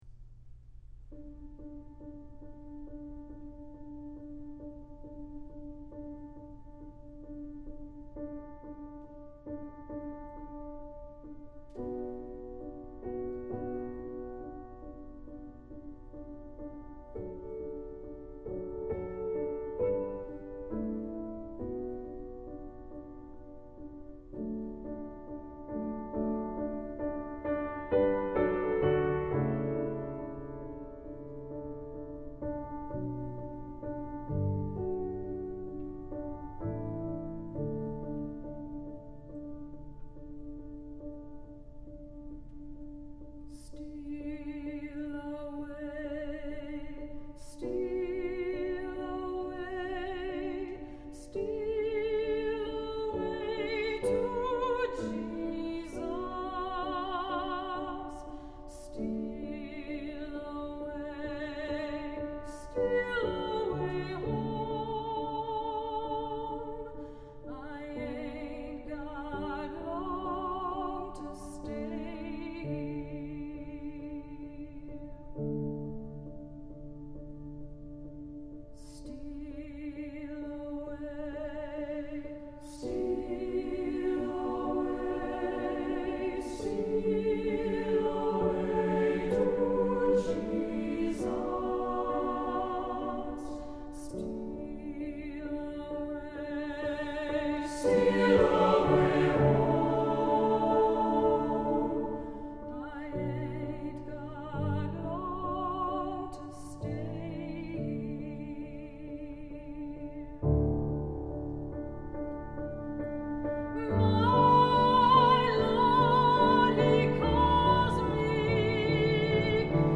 Composer: Spirituals
Voicing: SATB and Piano